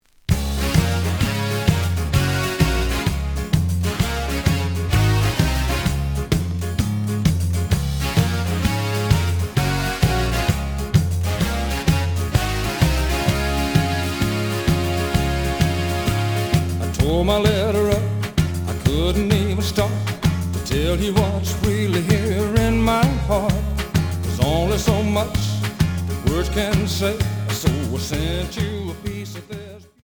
The audio sample is recorded from the actual item.
●Format: 7 inch
●Genre: Folk / Country